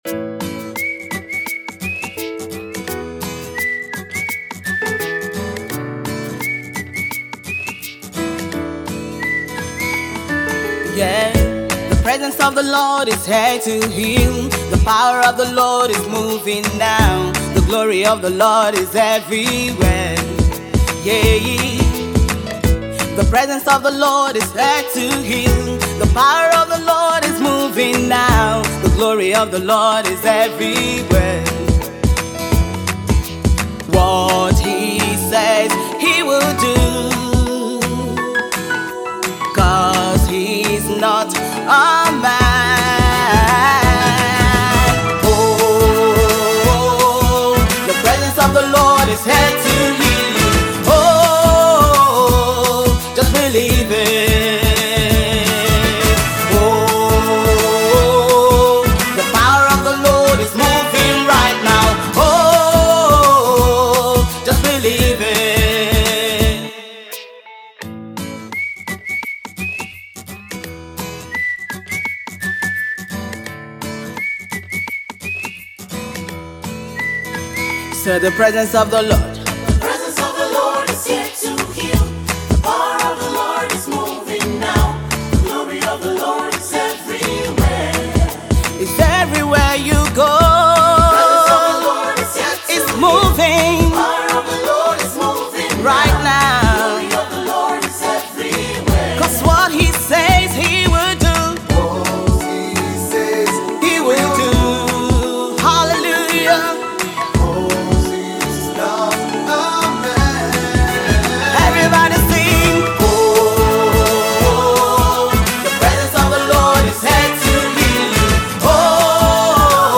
UK based worship leader and Songwriter